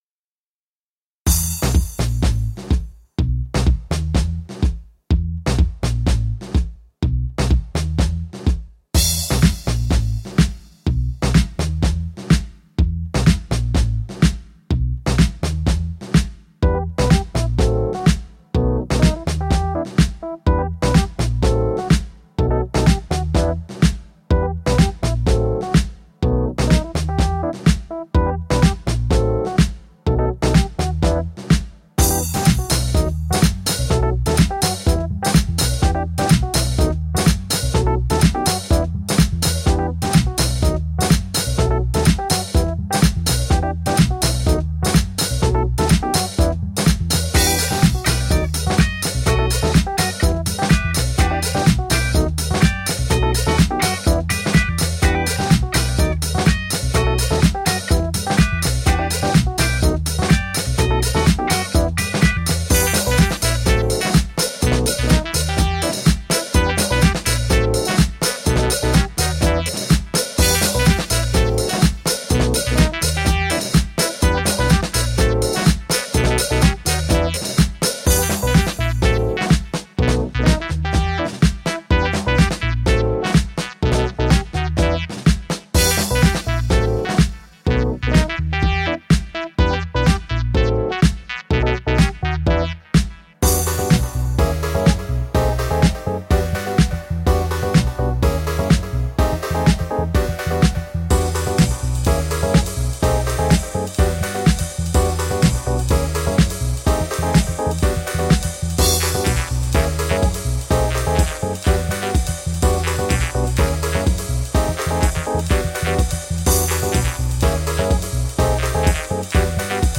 Jazzy eclectic and elegant dancefloor friendly electronica.
Tagged as: Jazz, Electronica, Chillout